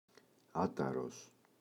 άταρος [‘ataros] – ΔΠΗ